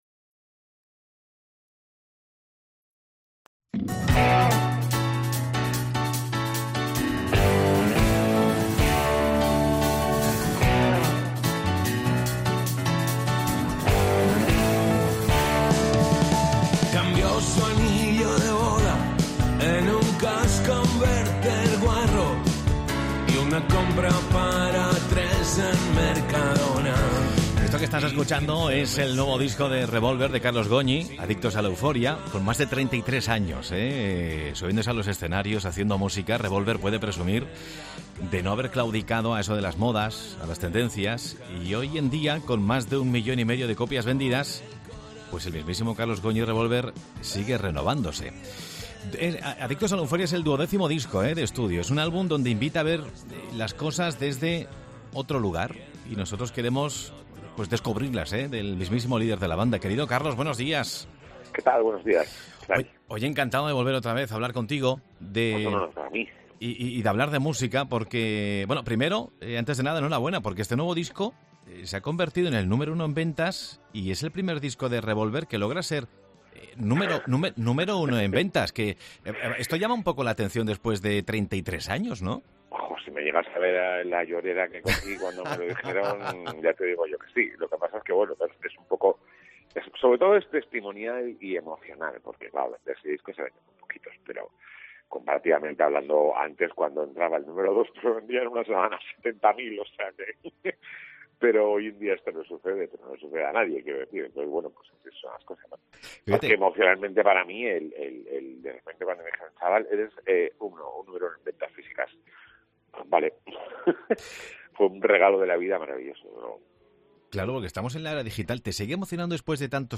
AUDIO: El vocalista de la banda Carlos Goñi explica en Mediodía COPE Alicante el cambio y transformación que ha vivido antes de grabar su nuevo disco...